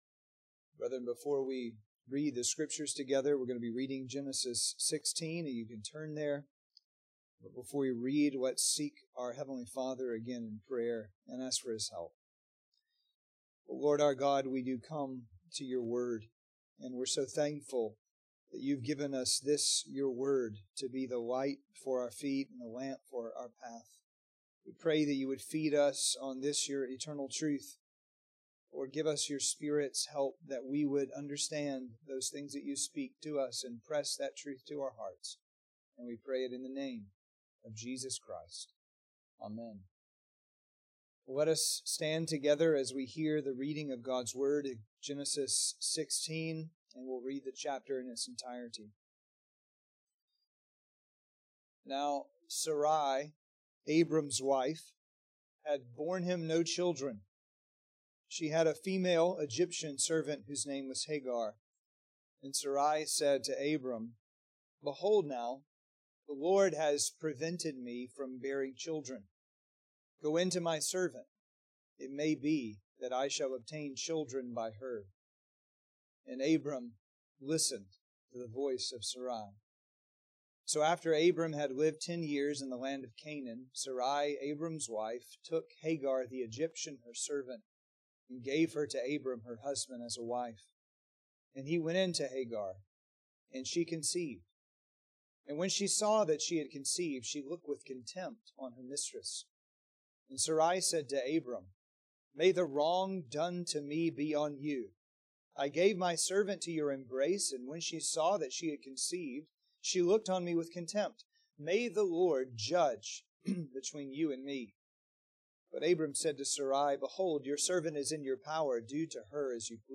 Sermons and Adult Sunday School from Grace Presbyterian Church, Douglasville, Georgia